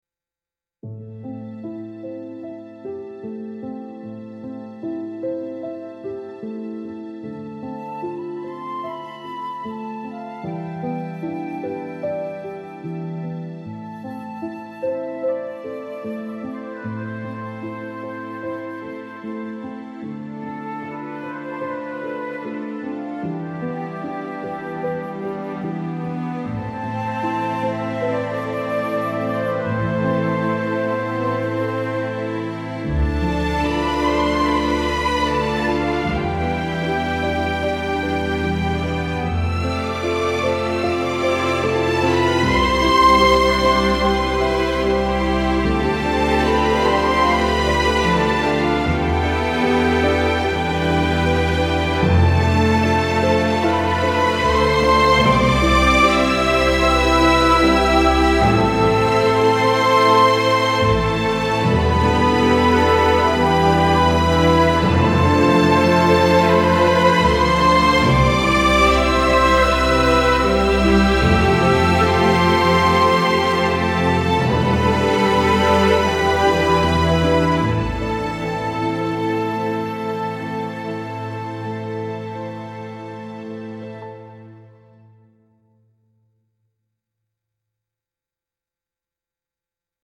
classic romantic orchestral piece with timeless elegance and warm strings